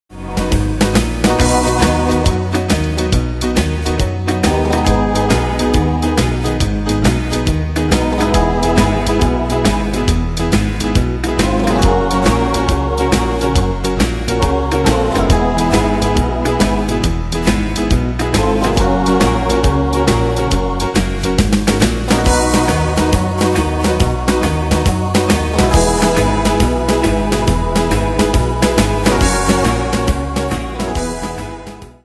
Style: Country Année/Year: 1994 Tempo: 138 Durée/Time: 2.25
Danse/Dance: Rock Cat Id.
Pro Backing Tracks